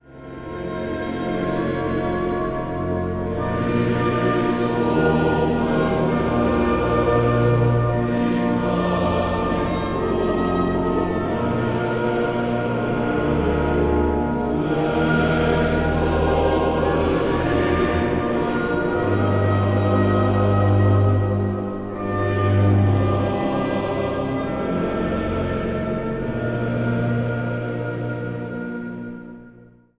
orgel
hobo
trompet.
Zang | Mannenkoor